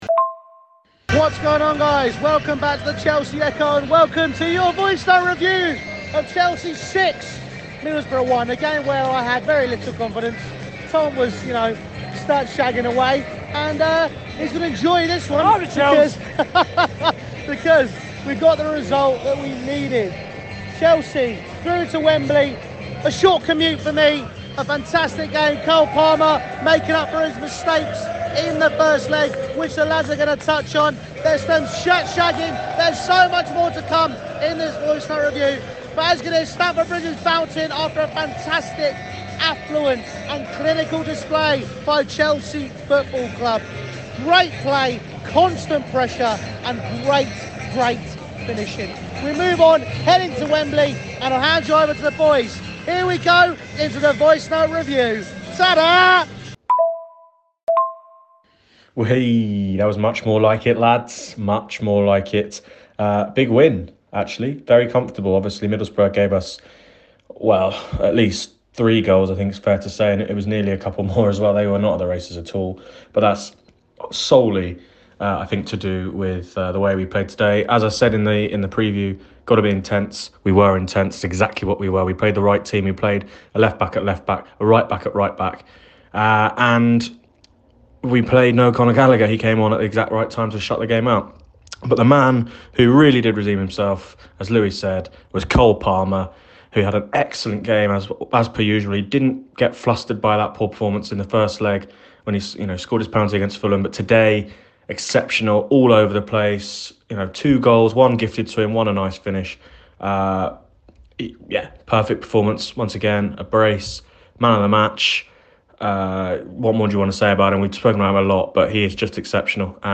Chelsea DEMOLISH Middlesbrough! | Chelsea 6-1 Middlesbrough Voicenote Review
Chelsea redeem themselves after the horrendous first-leg to book their ticket to the first cup final of the Boehly era. The boys give you their thoughts post-game and try to comprehend... SIX GOALS.